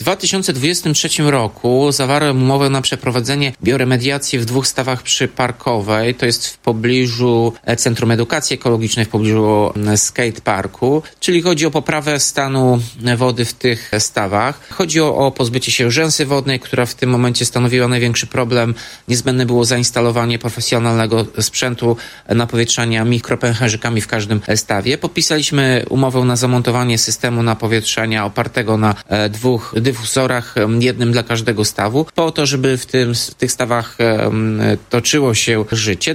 Mówił Tomasz Andrukiewicz, prezydent Ełku.